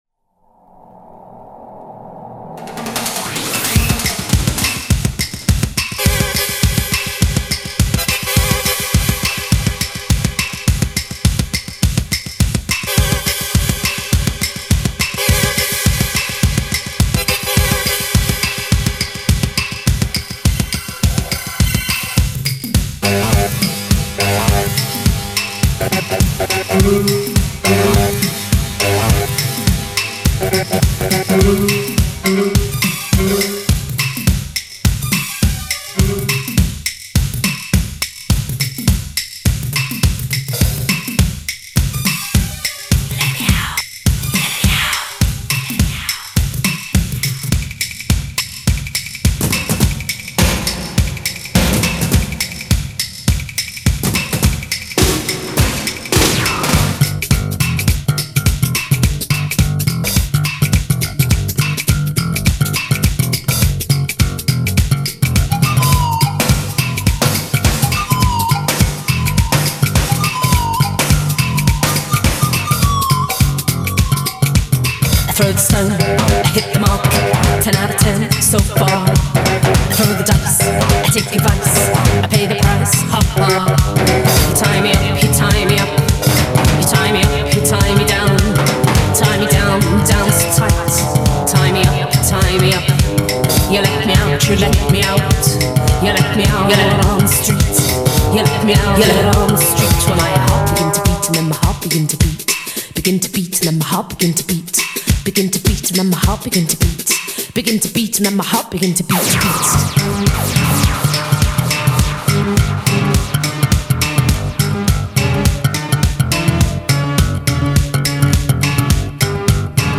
Genre: Synthpop.